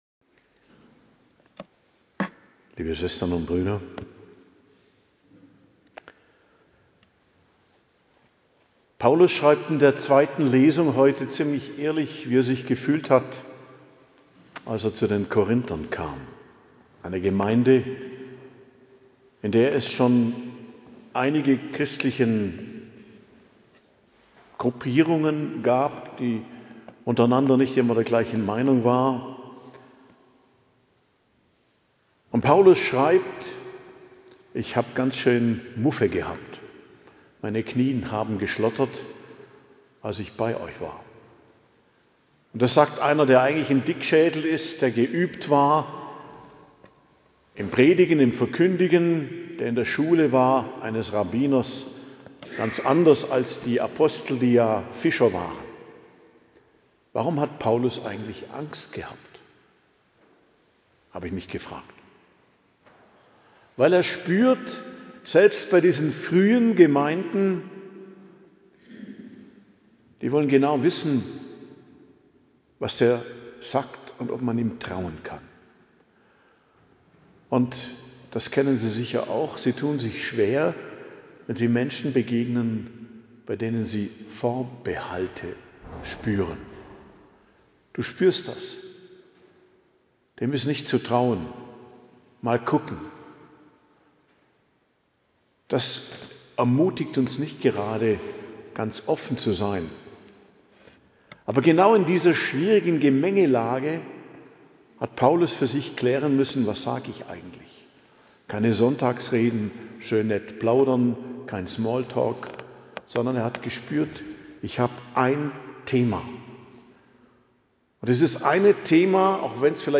Predigt zum 5. Sonntag i.J., 8.02.2026 ~ Geistliches Zentrum Kloster Heiligkreuztal Podcast